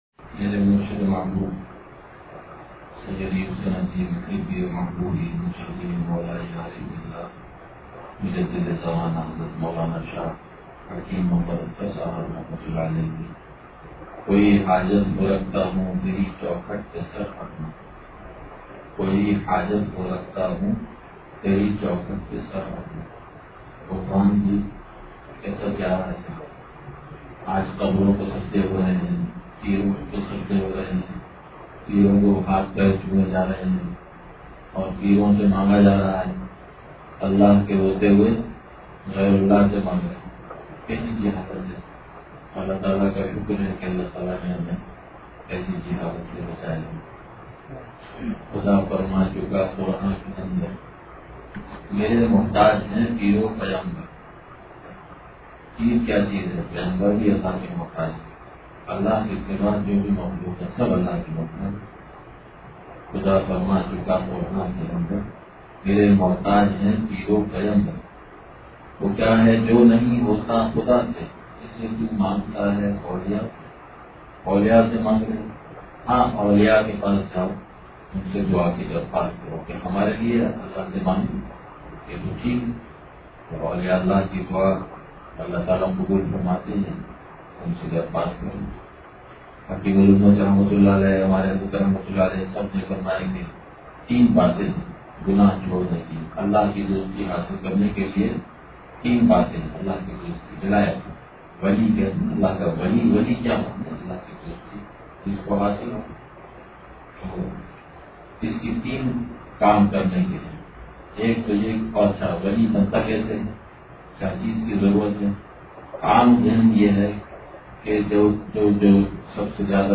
ختمِ قرآن کے موقع پر بیان